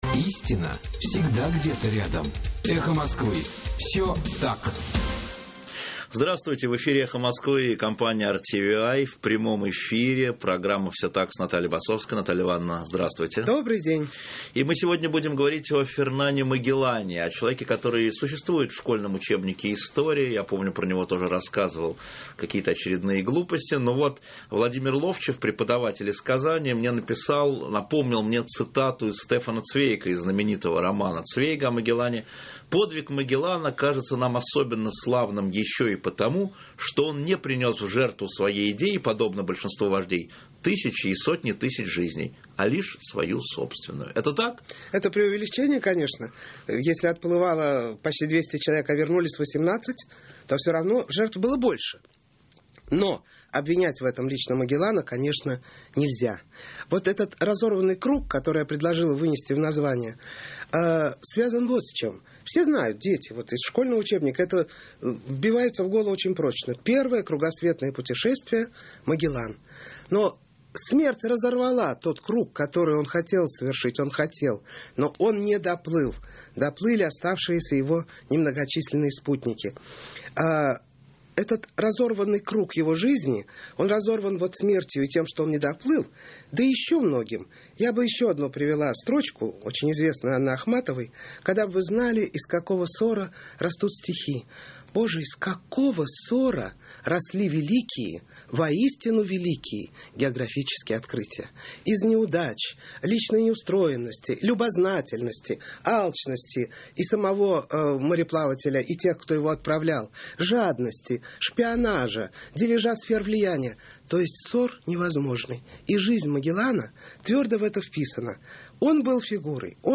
В эфире «Эхо Москвы» и компании RTVi в прямом эфире программа «Все так» с Натальей Басовской.